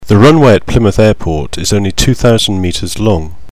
The learner can hear the word and the sentence pronounced in either a British or an American accent.